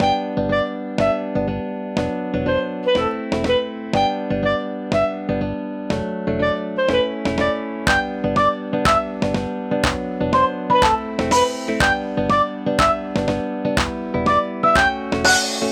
楽しいフェスティバルをイメージしました！気分の上がる元気な１曲です！
ループ：◎
BPM：122 キー：G ジャンル：あかるい 楽器：ファンタジー